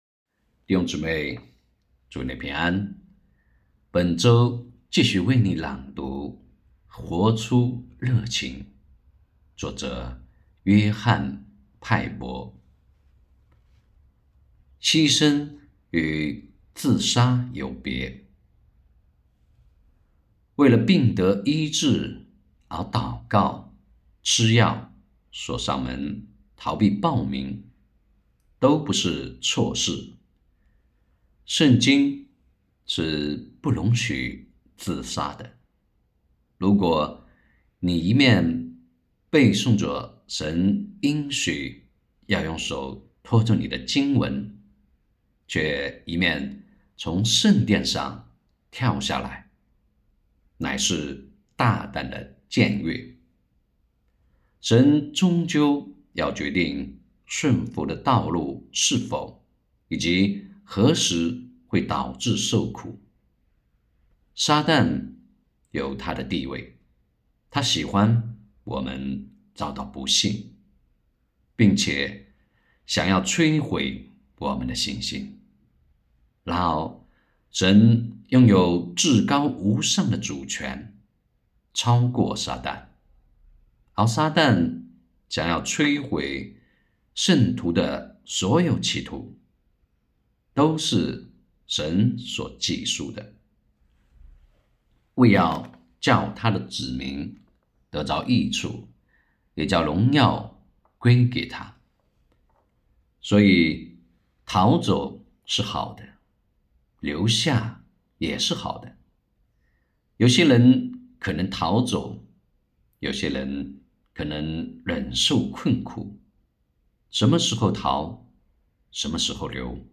2023年12月28日 “伴你读书”，正在为您朗读：《活出热情》 音频 https